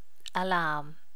alarm.wav